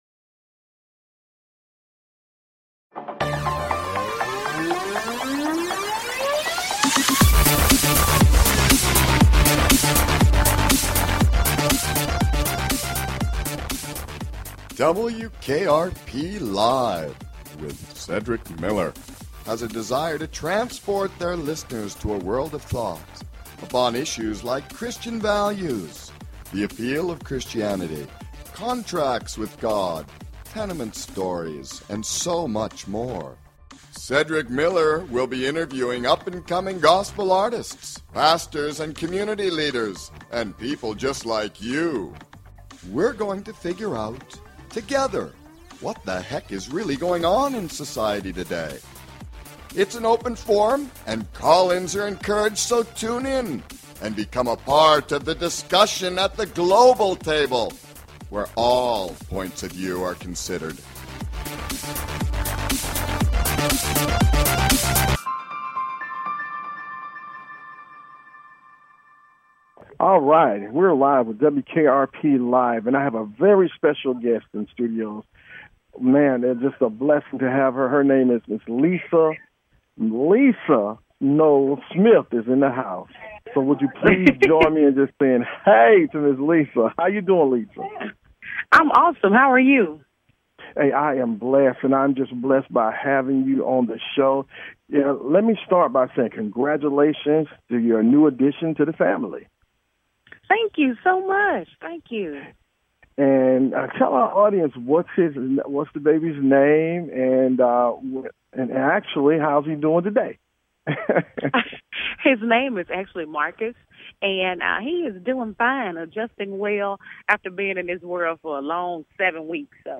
Talk Show Episode
interviewing singer